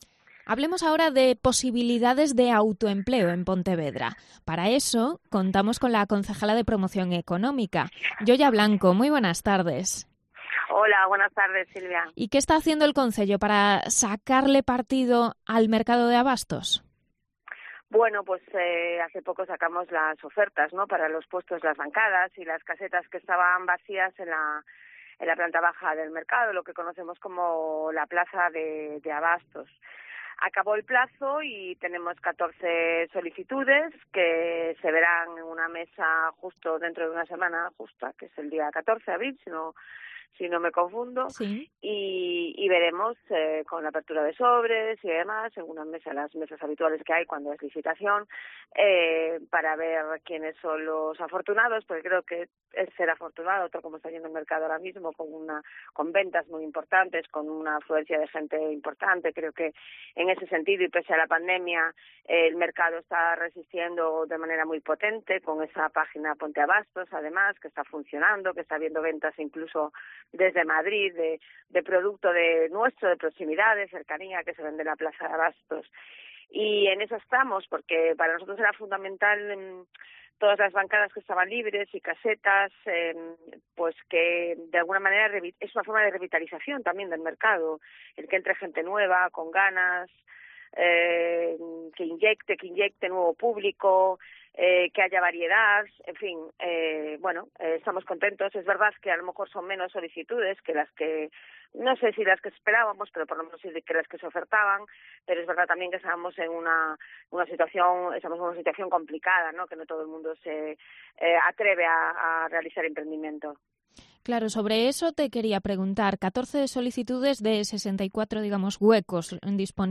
Entrevista con la concejala socialista Yoya Blanco sobre las posibilidades de creación de empleo en puestos vacíos de la Plaza de Abastos de Pontevedra.